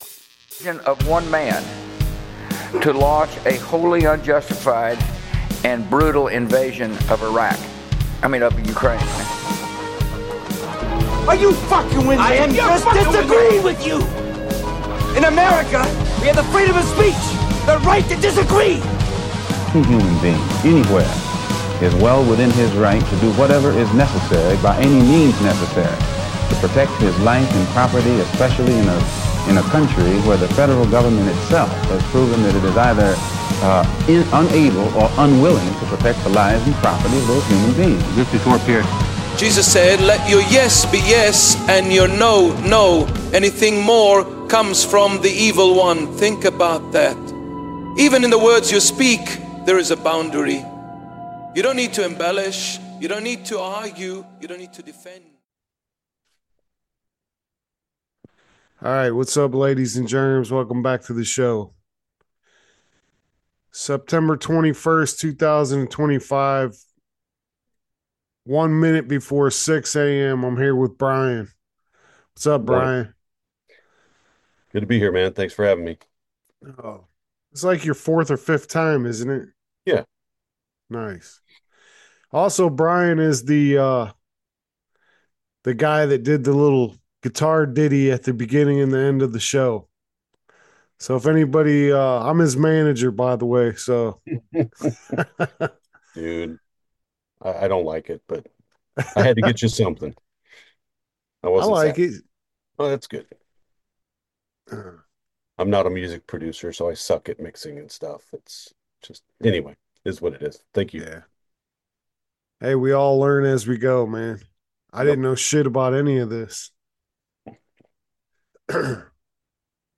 Grunt style returns. September roundtable.
Occasional guest interviews.